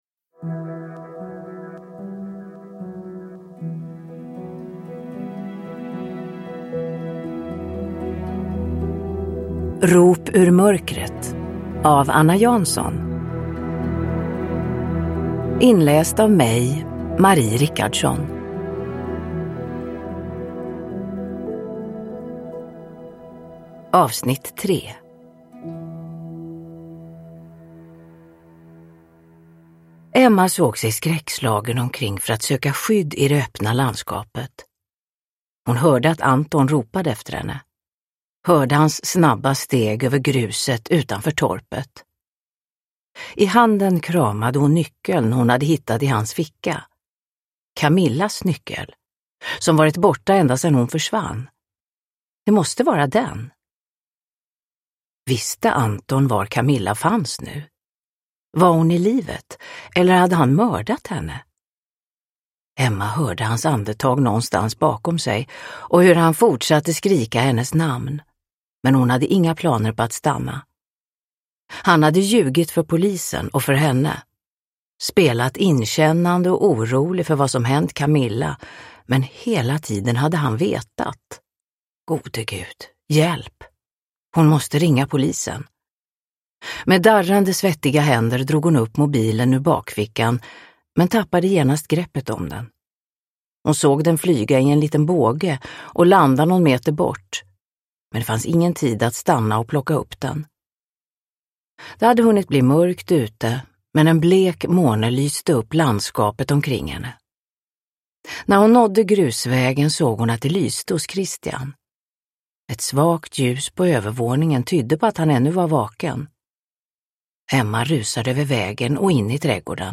Rop ur mörkret - 3 – Ljudbok – Laddas ner
Uppläsare: Marie Richardson